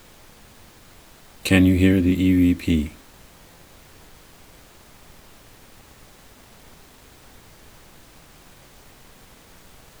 They start out simple, with a decent signal strength but they get progressively harder to make out as the signal becomes weaker and embedded deeper into the noise floor.